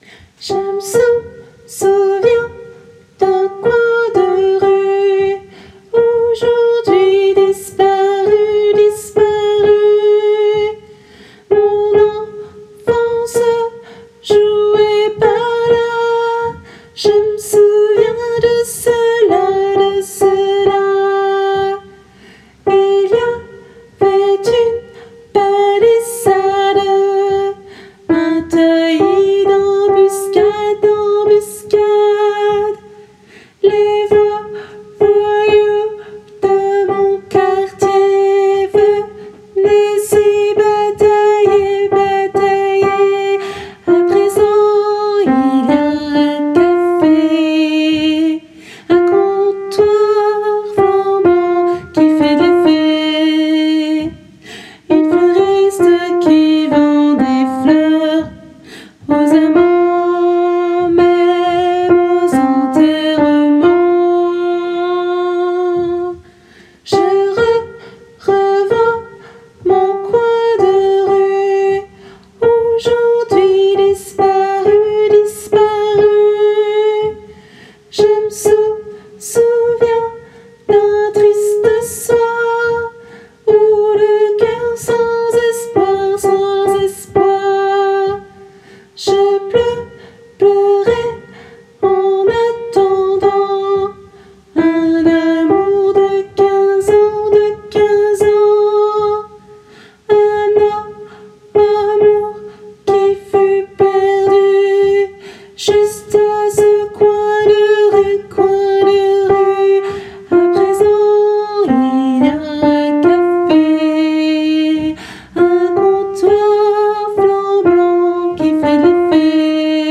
Mp3 versions chantées
Alto